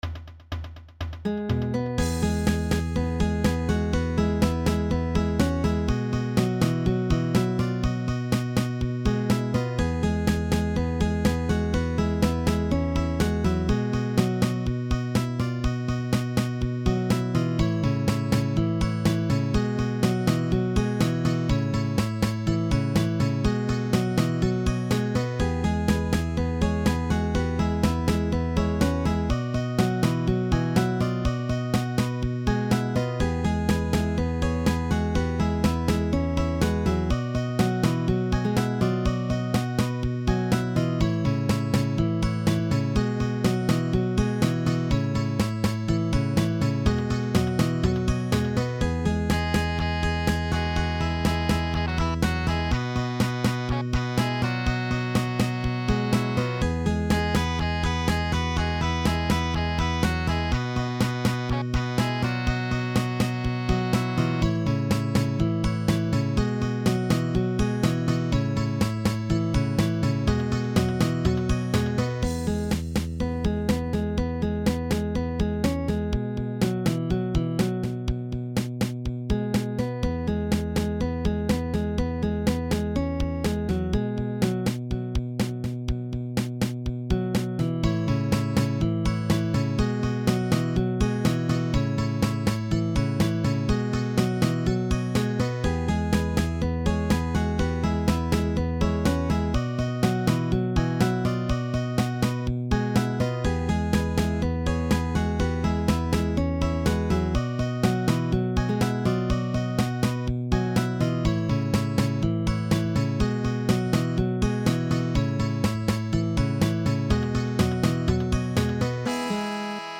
A MIDI composition